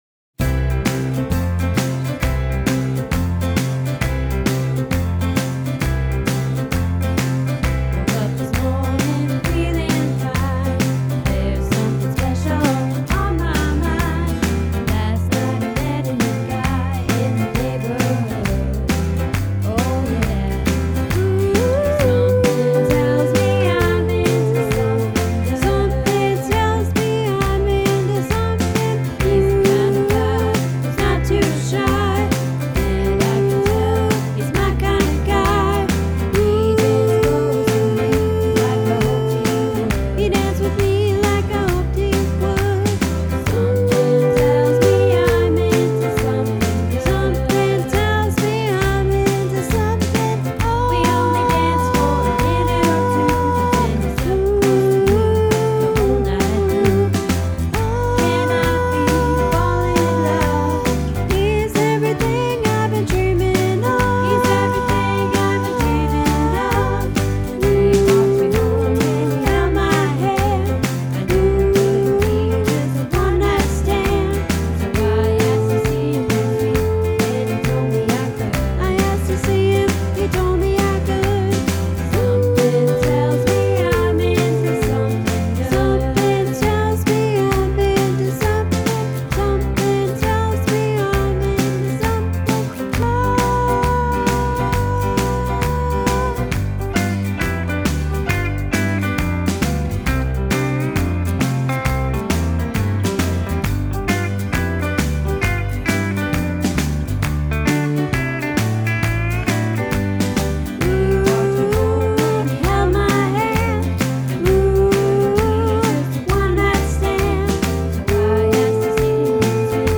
Into Something Good - Soprano